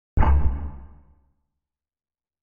جلوه های صوتی
دانلود آهنگ تانک 11 از افکت صوتی حمل و نقل
دانلود صدای تانک 11 از ساعد نیوز با لینک مستقیم و کیفیت بالا